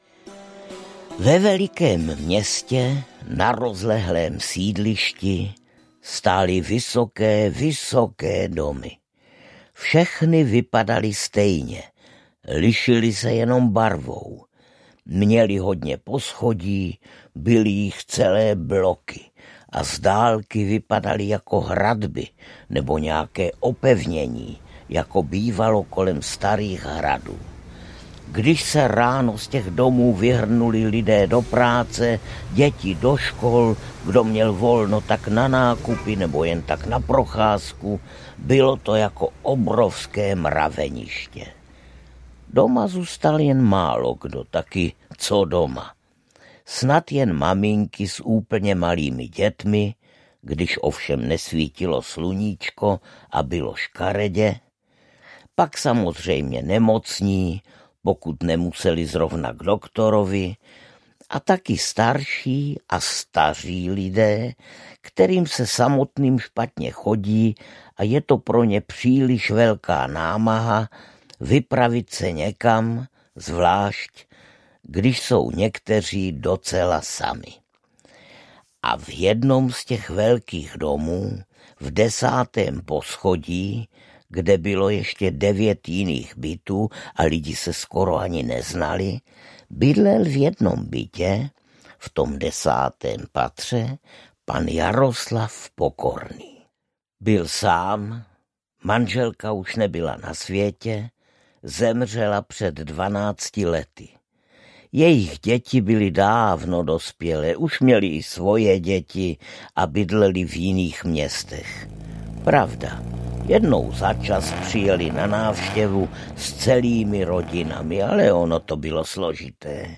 Příhoda málo uvěřitelná audiokniha
Ukázka z knihy
• InterpretArnošt Goldflam